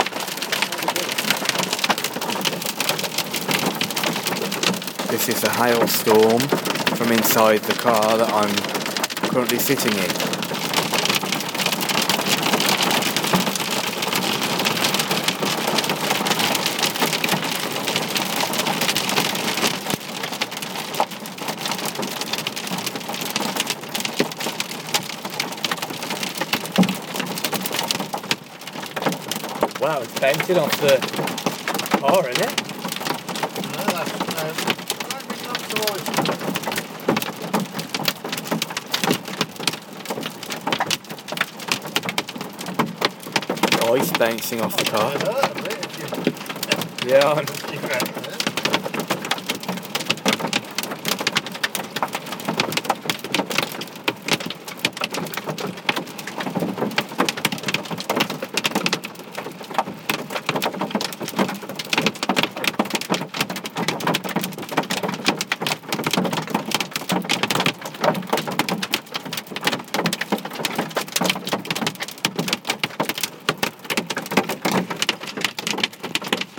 Hail storm